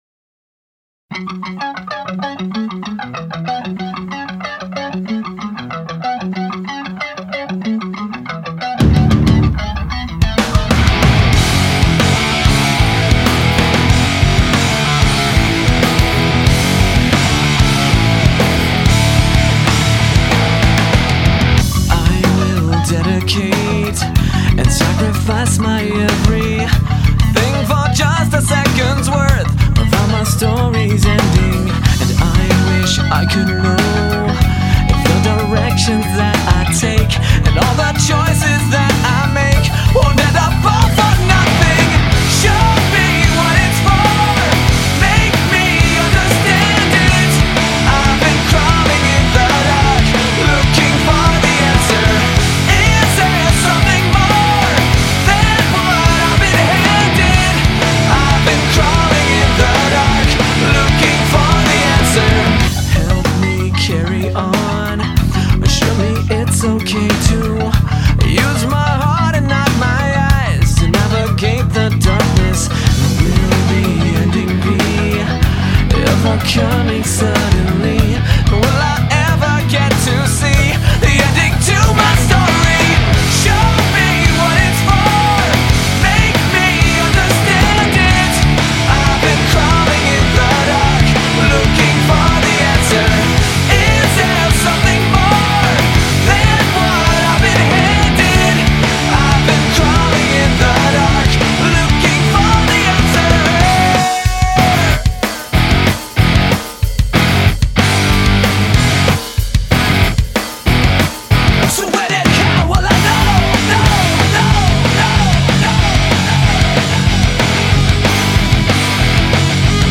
Категория: Rock, Alternative